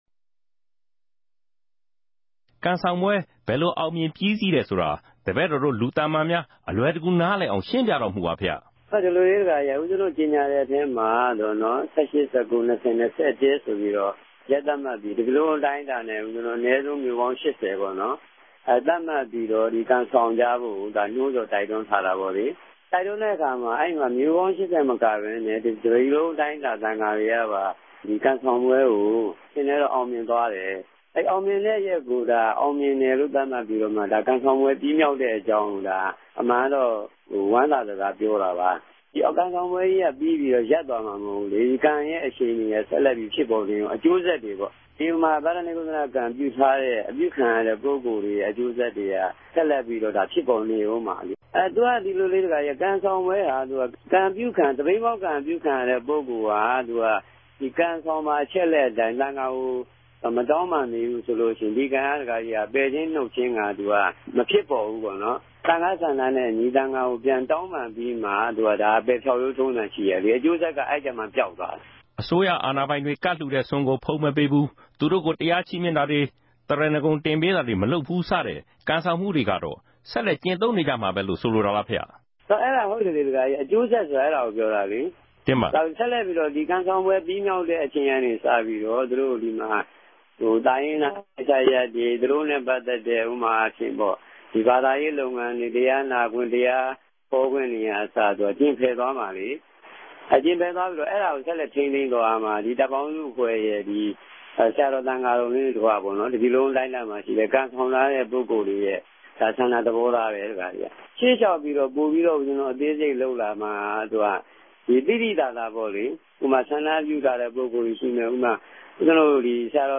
လမ်းလ္တေွာက့်ကခဵီနေတဲ့ုကားကပဲ တယ်လီဖုန်းနဲႛ သံဃာတော်တပၝးက RFA မိန်ႛုကားခဲ့တာ ူဖစ်ပၝတယ်။ ဒီကနေႛ့ကခဵီရာမြာ ပၝဝင်ခဲ့တဲ့ နောက်ထပ် ဦးဇင်းတပၝးကလည်း မိမိတိုႛအောင်ူမင်စြာ ကံဆောင်ခဲ့ုကေုကာင်း၊ ူပည်သူတြေ အထူးသူဖင့် လူငယ်တြေ ပိုမိုပၝဝင်လာခဲ့ုကေုကာင်း ေူပာပၝတယ်။